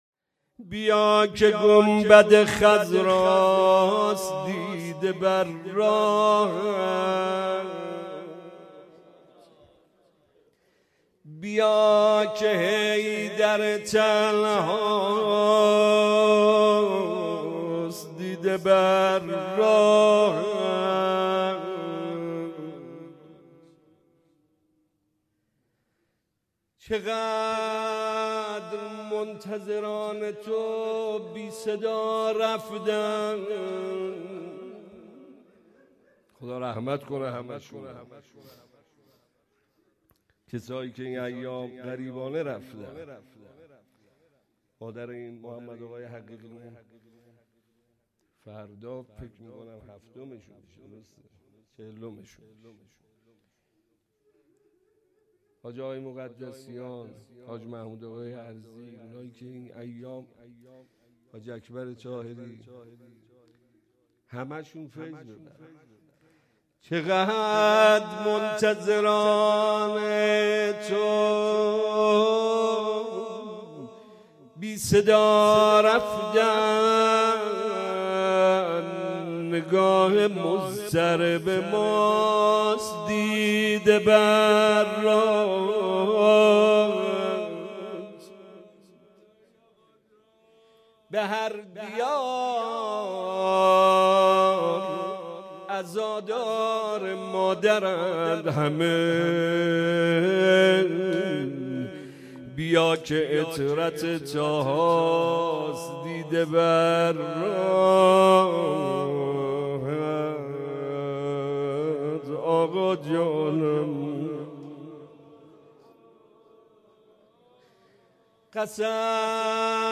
مداحی
شب دوم فاطمیه